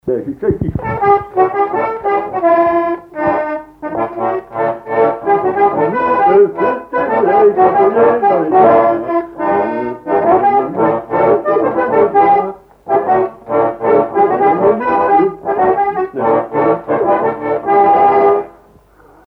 Commequiers ( Plus d'informations sur Wikipedia ) Vendée
danse : mazurka
accordéon diatonique
Pièce musicale inédite